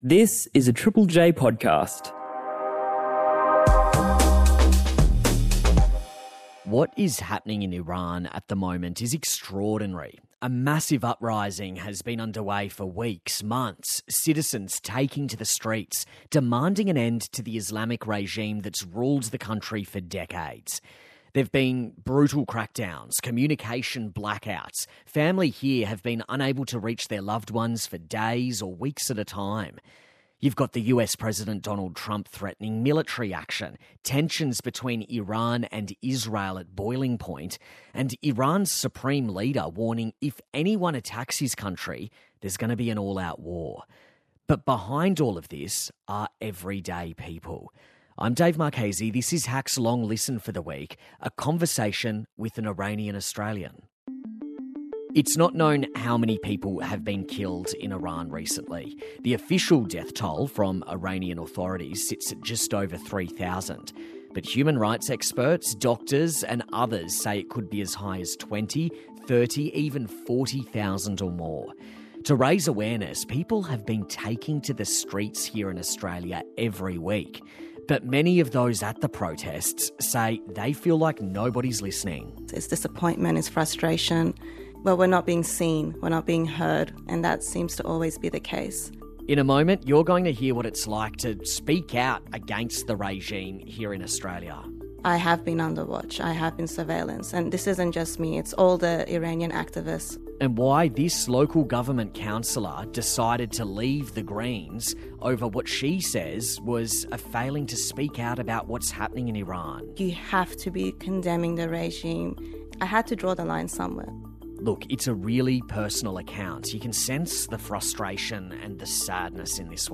INTERVIEW: The Iranians being silenced in Australia